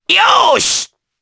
Oddy-Vox_Happy4_jp.wav